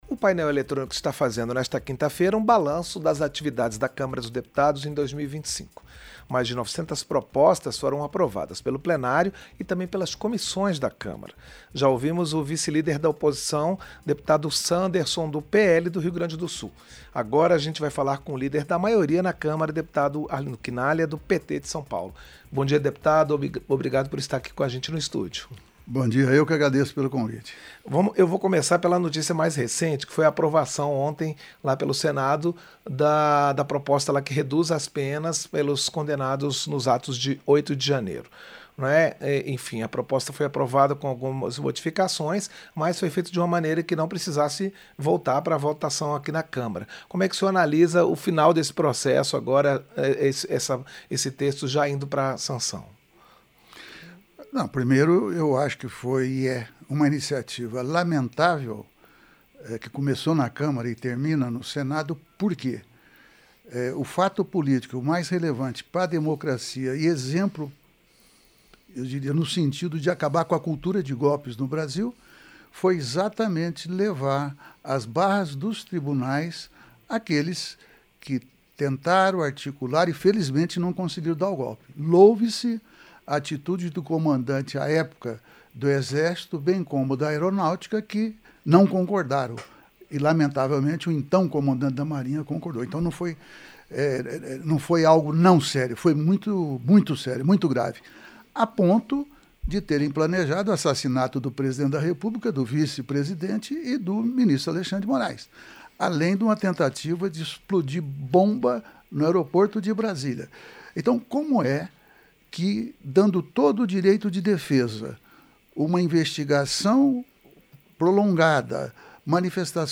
Entrevista - Dep. Arlindo Chinaglia (PT-SP)